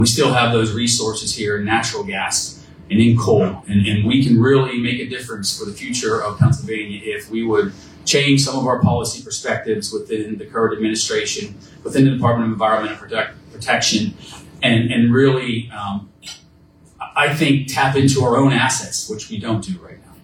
State Senator Joe Pittman and State Representative Jim Struzzi talked about the budget impasse at Eggs and Issues event hosted by the Indiana County Chamber of Commerce this morning.